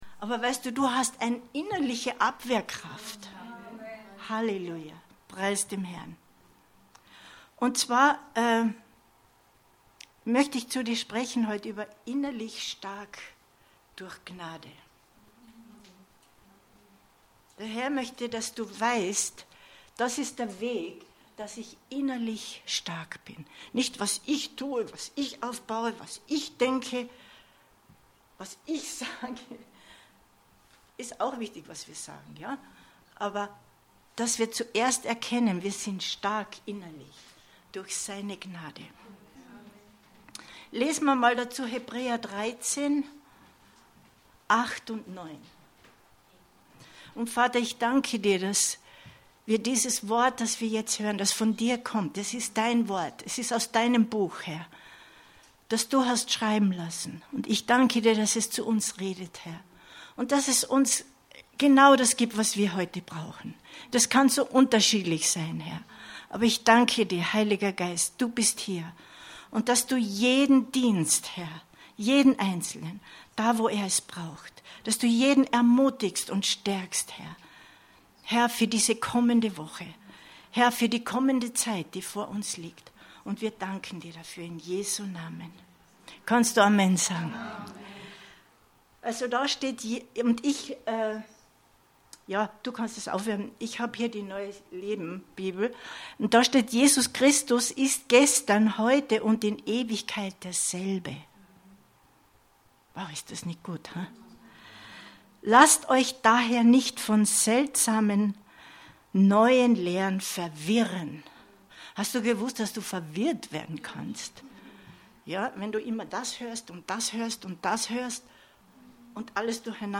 Info Info Innerlich stark durch Gnade 19.09.2021 Predigt herunterladen